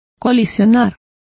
Complete with pronunciation of the translation of colliding.